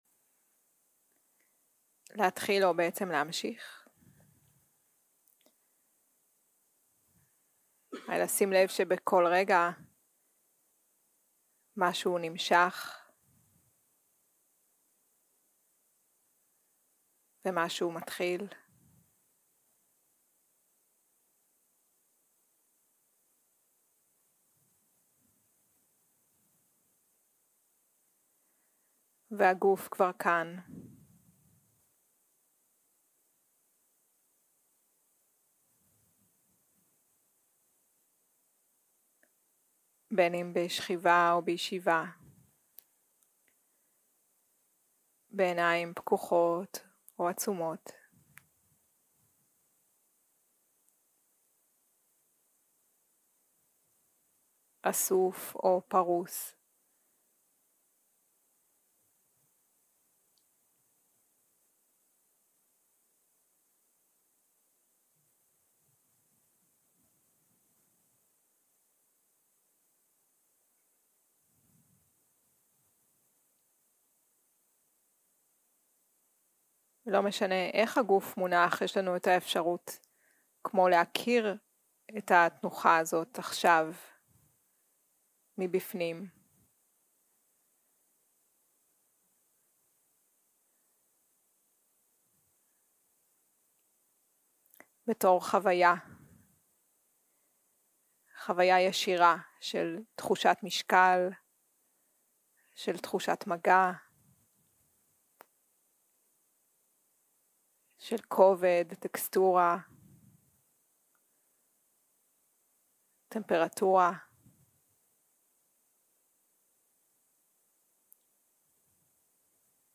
יום 2 - הקלטה 3 - צהרים - מדיטציה מונחית
סוג ההקלטה: מדיטציה מונחית
ריטריט ויפסנא "מנוחה עמוקה"